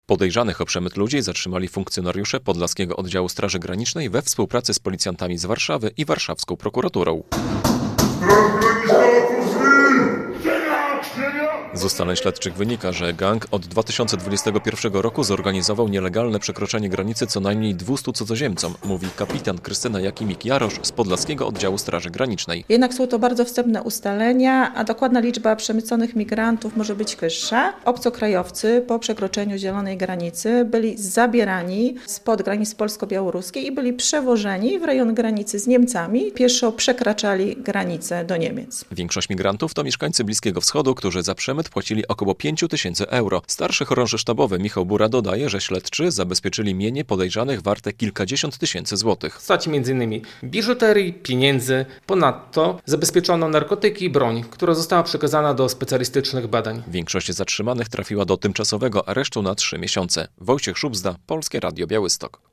Służby rozbiły gang przemytników imigrantów - relacja